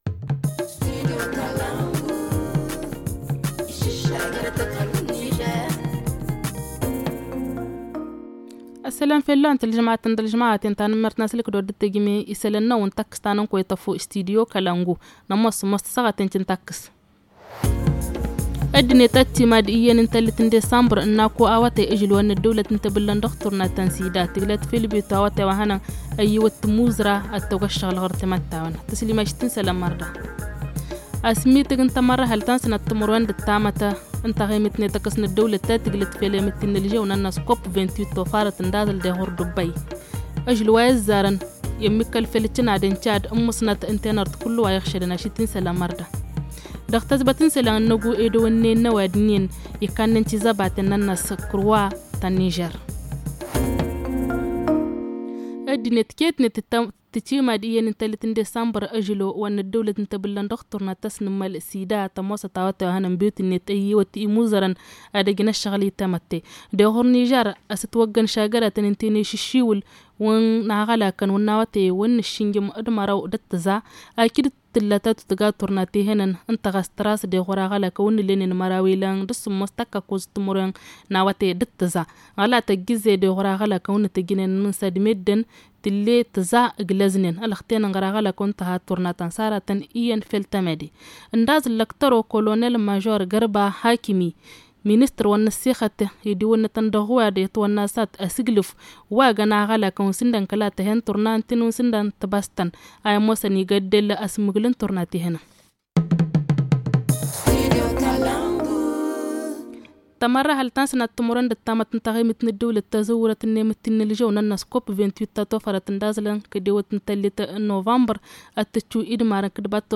Les titres FR TA FU HA ZA Le journal en français Le journal en tamasheq Le journal en fulfuldé Le journal en haoussa Le journal en zarma